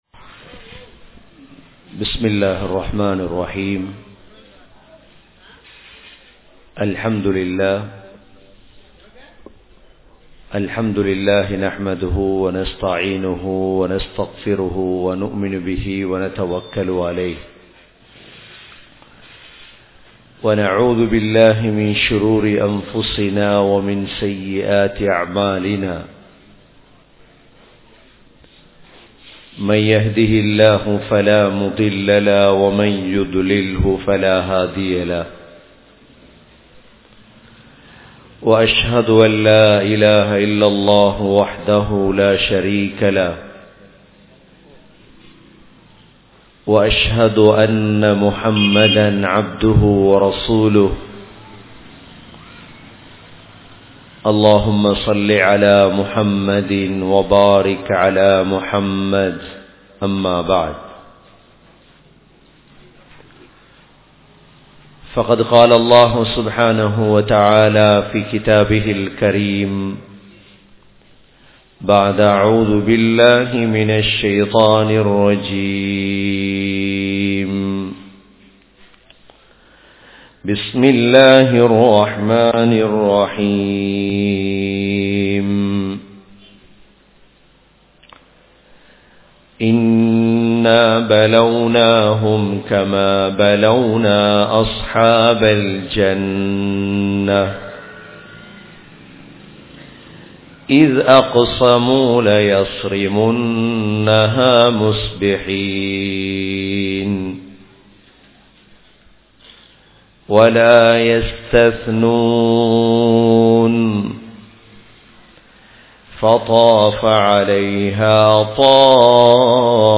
Zakath & Sathaqa (ஸகாத் & சதகா) | Audio Bayans | All Ceylon Muslim Youth Community | Addalaichenai